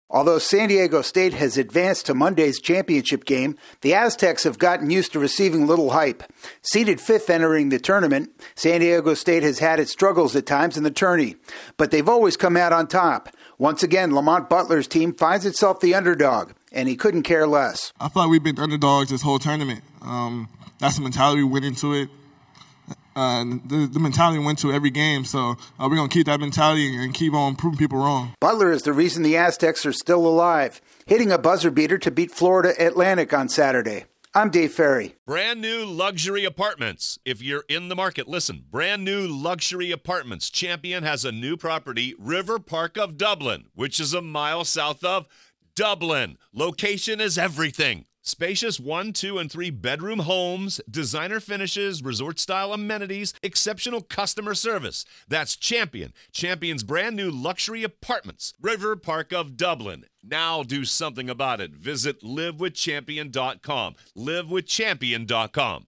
San Diego State has gotten used to being underdogs in the NCAA Tournament. AP correspondent